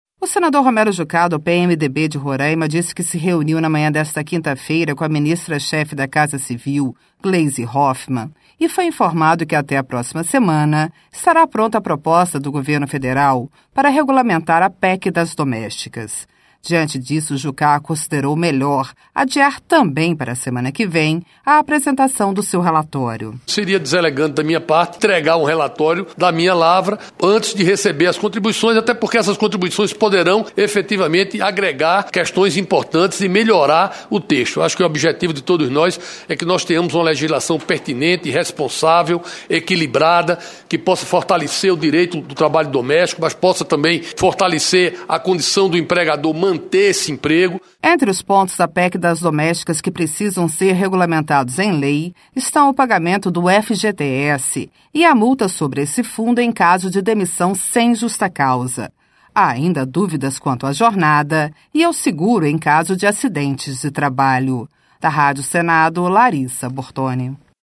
(Repórter)